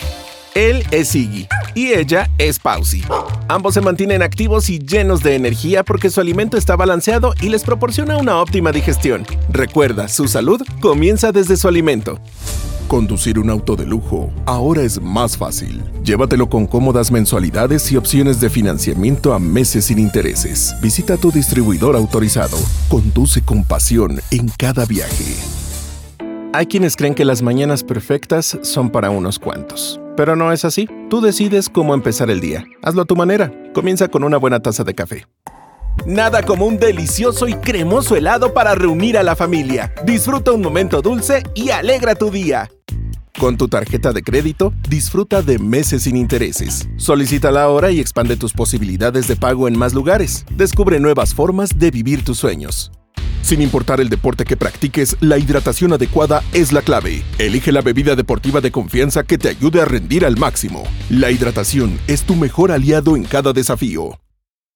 Spanisch (Mexikanisch)
Präzise
Artikulieren